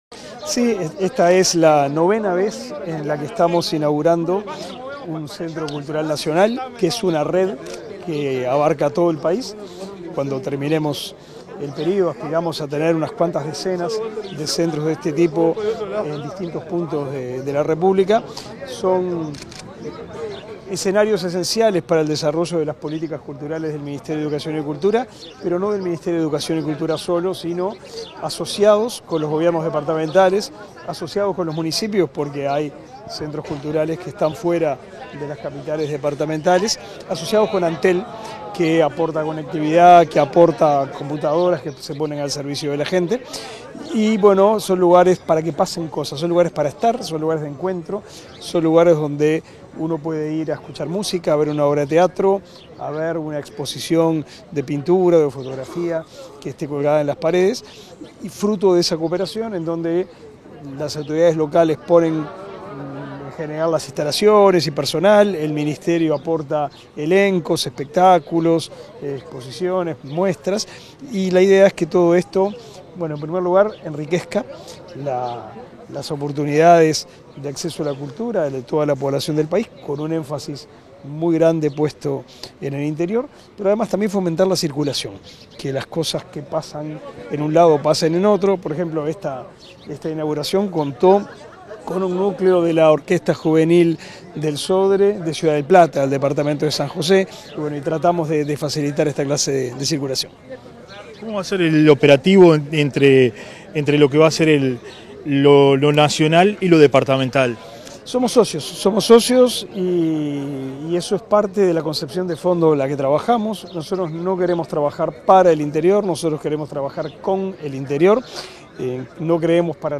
Declaraciones a la prensa del ministro de Educación y Cultura
Declaraciones a la prensa del ministro de Educación y Cultura 15/09/2022 Compartir Facebook Twitter Copiar enlace WhatsApp LinkedIn La directora nacional de Cultura, Mariana Wainstein, y el ministro de Educación y Cultura, Pablo da Silveira, participaron en el acto de nominación del Centro Cultural de AFE de Colonia del Sacramento como Centro Cultural Nacional. Luego el secretario de Estado dialogó con la prensa.